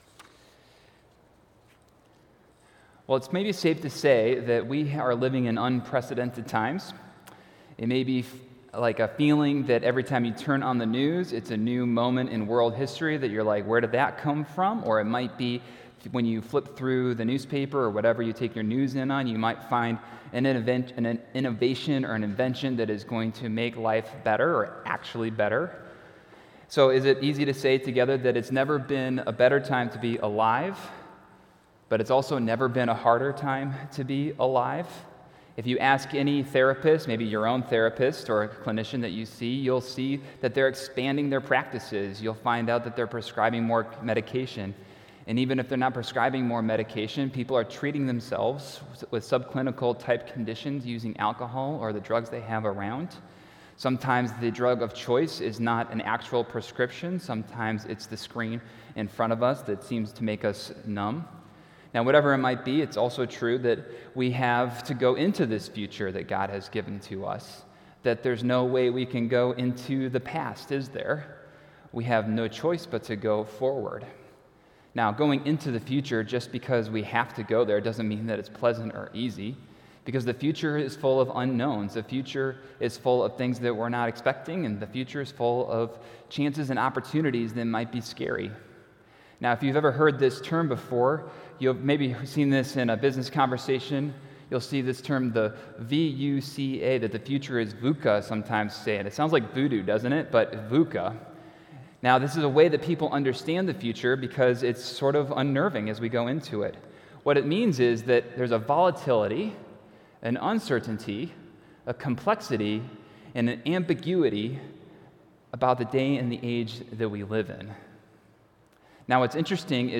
Sunday, September 22, 2019 – A Church For the Future Week 1 – Park Ridge Presbyterian Church